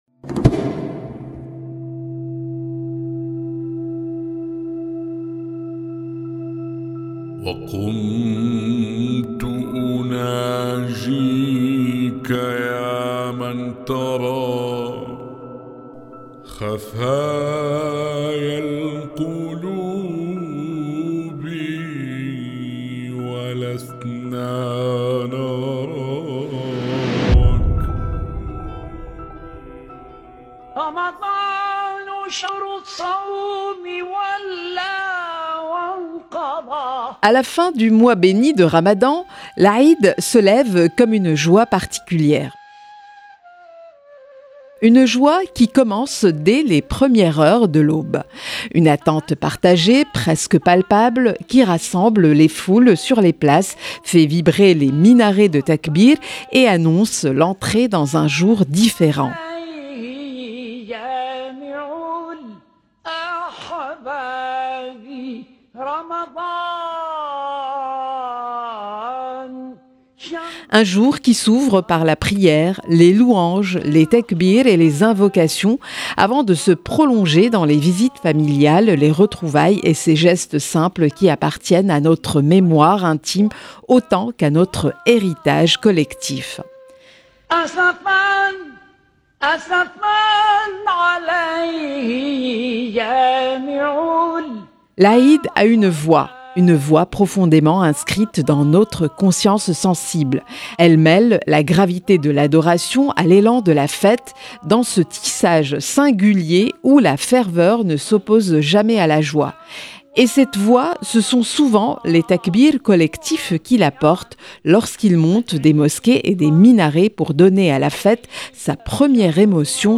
Avec les premières lueurs de l’aube de l’Aïd, les takbîrât s’élèvent, mêlant la solennité du culte à la joie de la fête. Dans cet épisode, nous explorons la richesse des variations sonores de cet appel : du système de rotation dans la Grande Mosquée de La Mecque, à l’interprétation collective rapide à Damas, jusqu’aux modes andalous du Maghreb et à l’échelle pentatonique en Afrique.